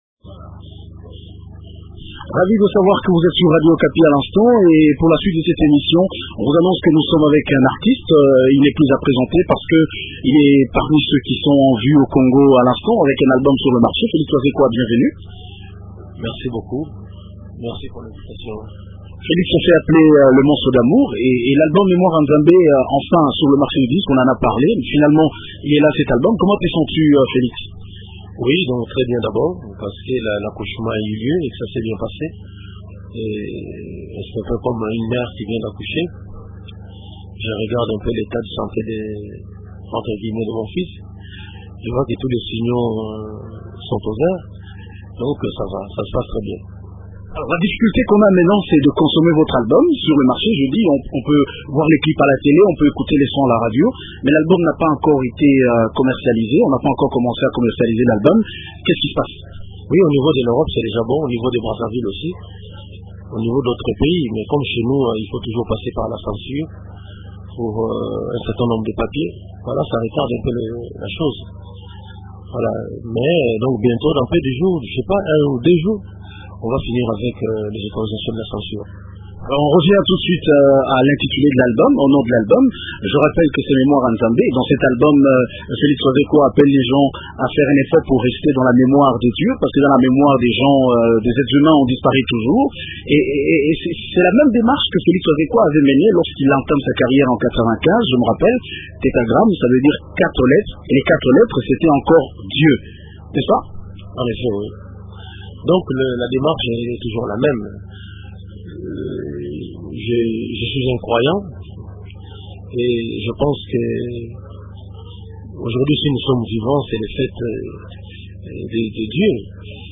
Félix Wazekwa, artiste musicien congolais
Ecoutez Félix Wazekwa dans cet entretien diffusé, samedi sur Radio Okapi: Fichier audio : téléchargez Flash pour écouter.